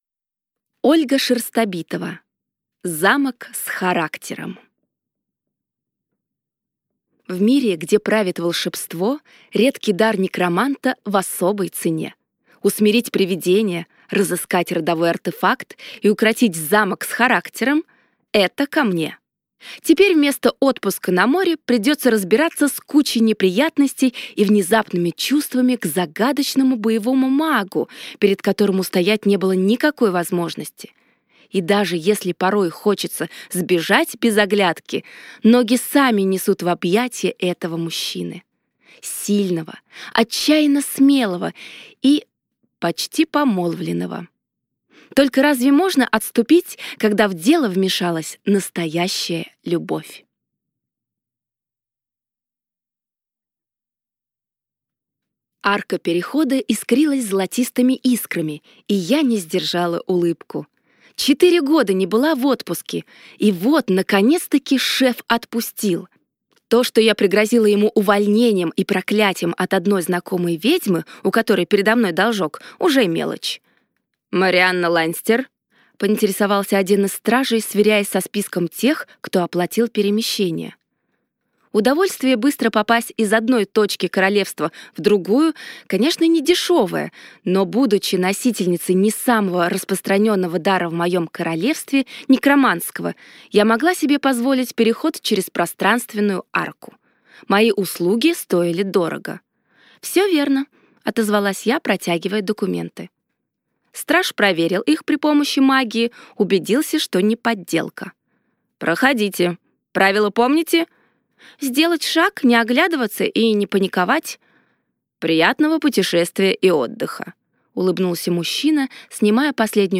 Аудиокнига Замок с характером | Библиотека аудиокниг